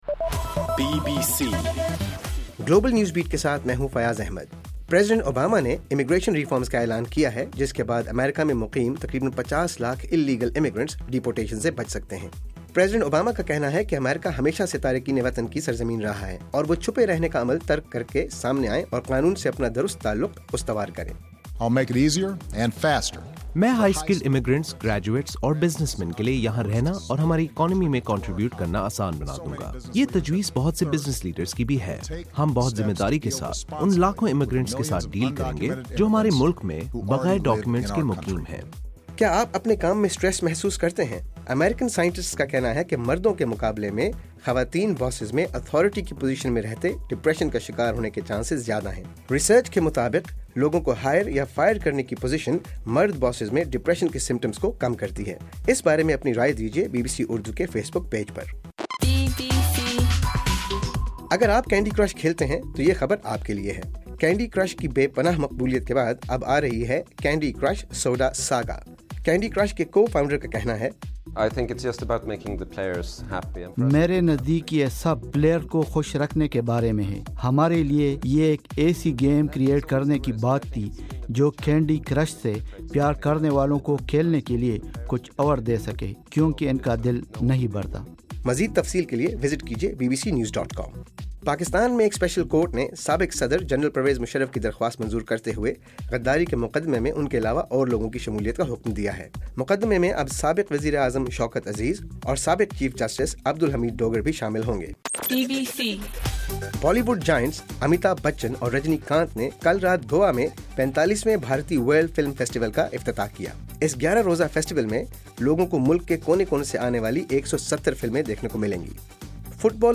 نومبر 21: رات 9 بجے کا گلوبل نیوز بیٹ بُلیٹن